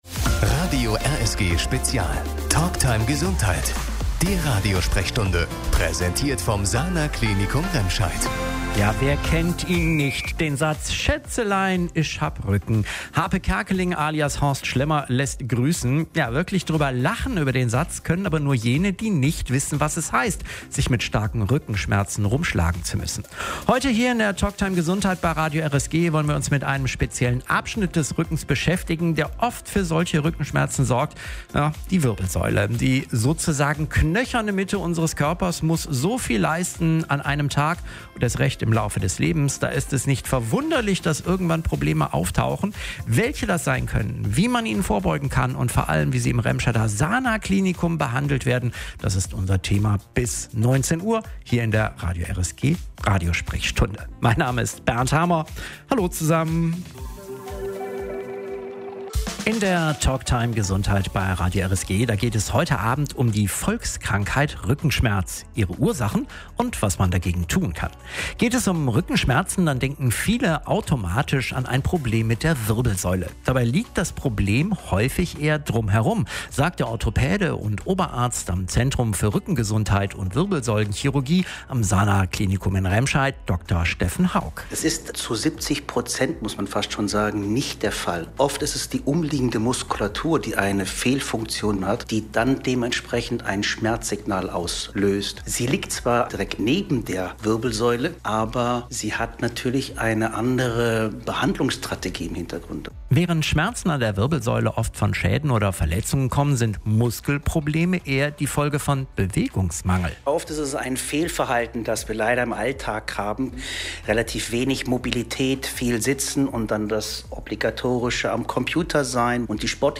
Die Sendung steht jetzt hier zum Nachhören bereit.